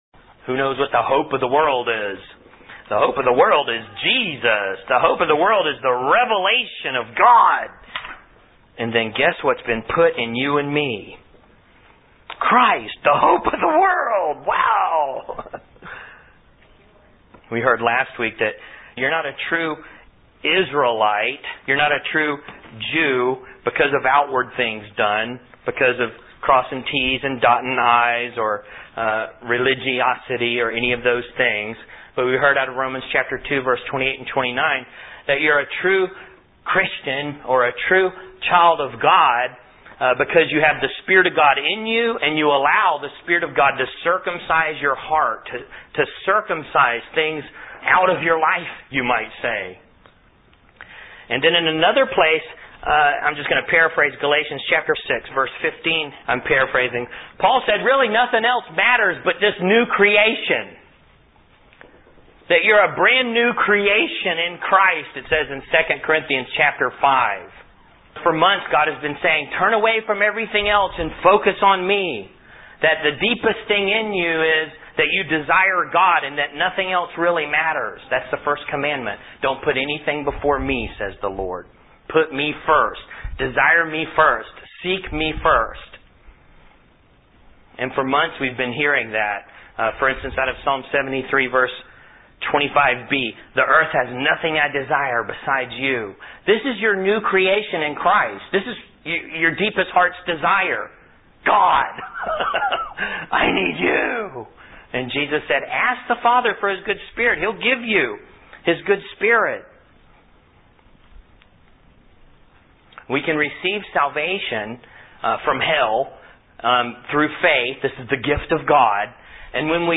your Canaan (whole 71-minute message)